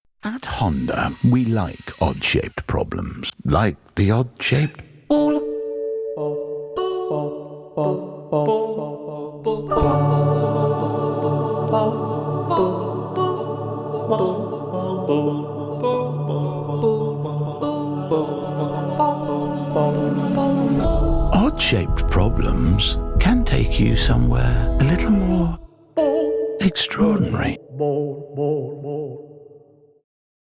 Yorkshire
Male
Deep
Dry
Gravelly
HONDA COMMERCIAL